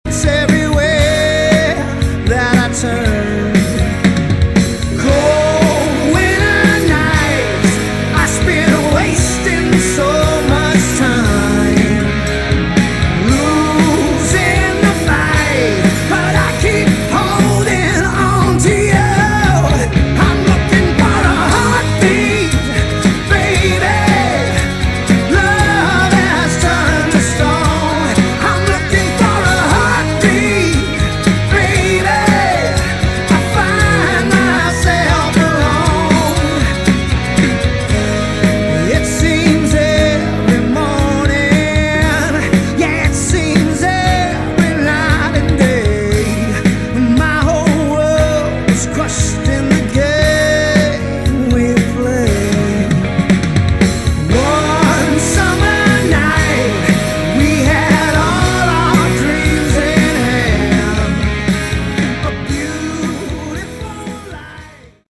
Category: Hard Rock
vocals, keyboard, guitar